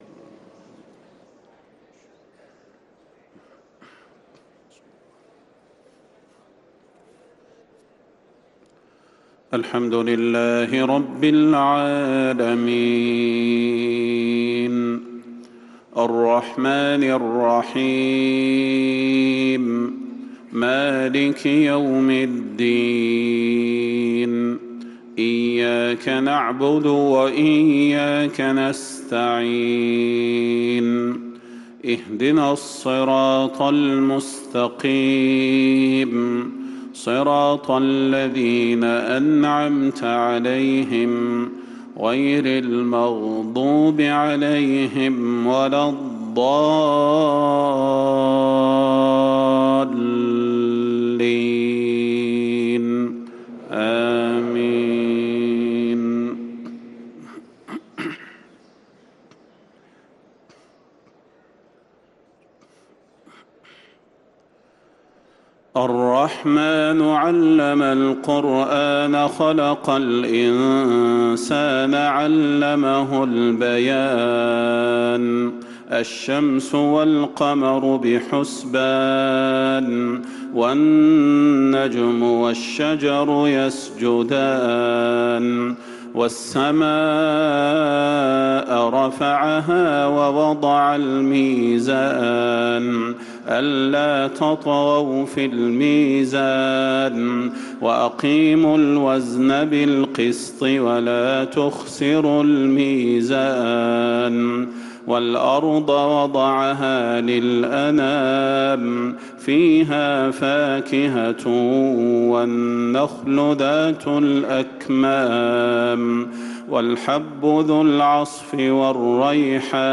صلاة الفجر للقارئ صلاح البدير 17 رجب 1445 هـ
تِلَاوَات الْحَرَمَيْن .